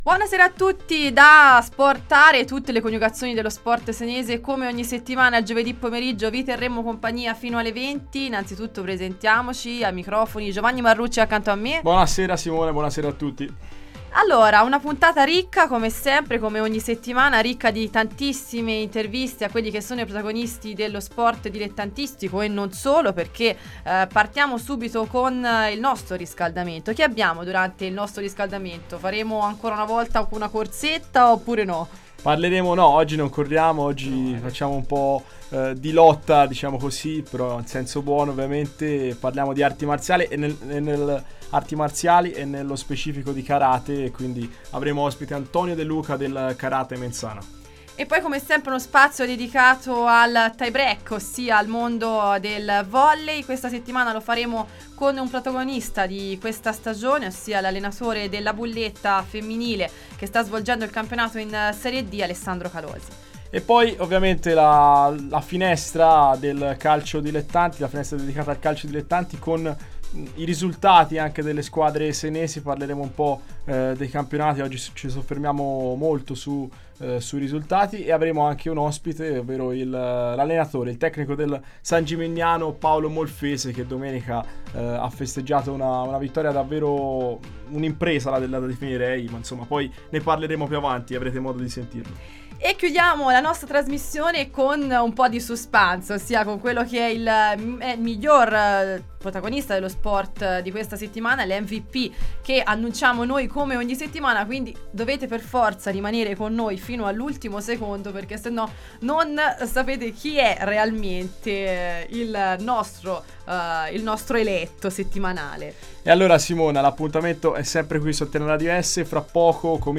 Terzo appuntamento questo pomeriggio con “SportARE – Tutte le coniugazioni dello sport senese”, la nuova trasmissione dedicata allo sport in onda ogni giovedì dalle 18 alle 20 sulle frequenze di Antenna Radio Esse.